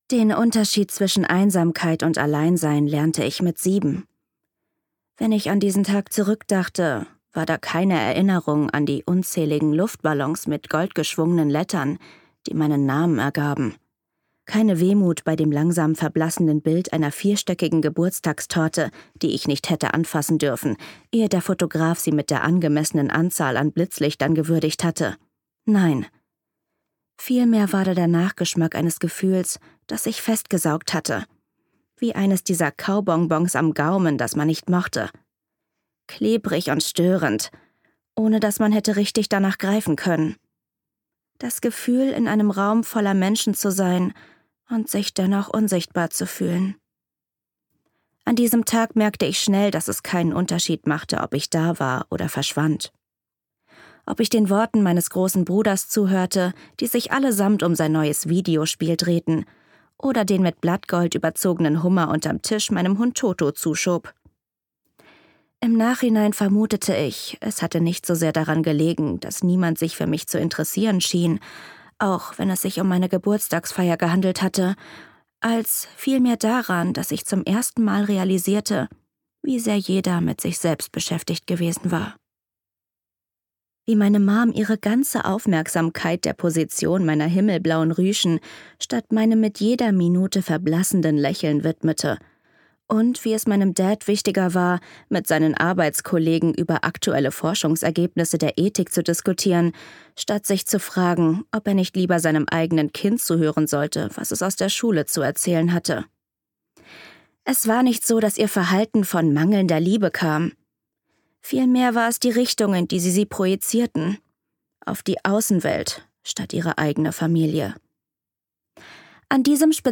Authentisch, gefühlvoll und mit einer Prise Spice – das finale Hörbuch der Rose Garden-Trilogie!